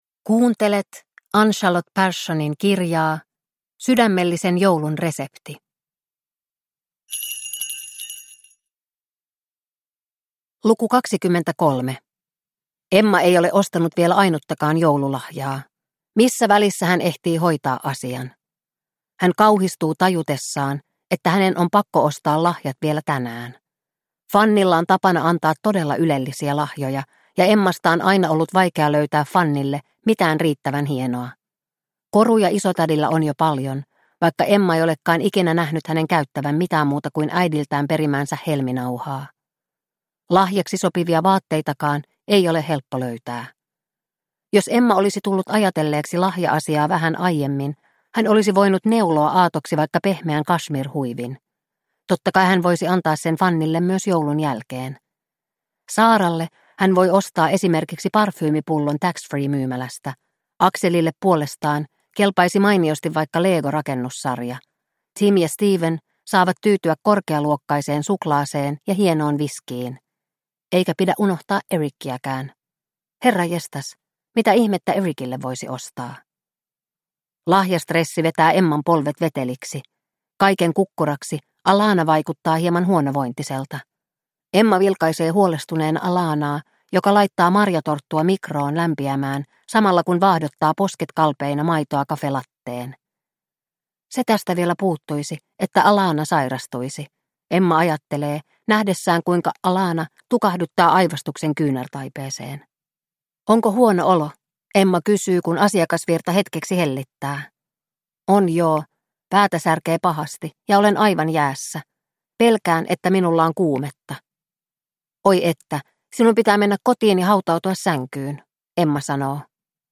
Sydämellisen joulun resepti - Luukku 23 – Ljudbok – Laddas ner